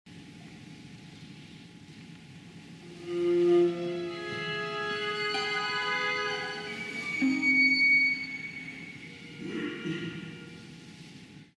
Het dynamische niveau is laag.